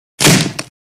Звуки выбивания двери
Звук удара в дверь